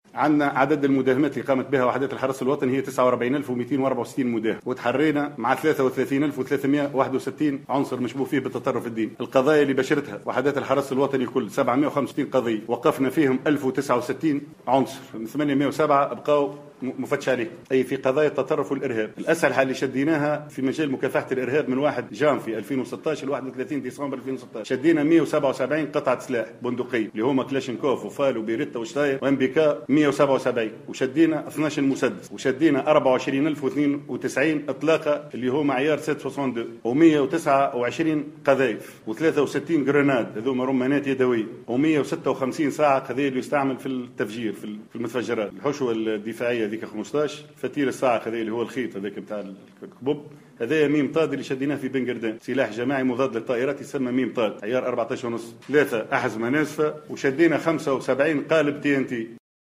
ندوة صحفية انعقدت اليوم الجمعة، بمقر الإدارة العامة لوحدات التدخل ببوشوشة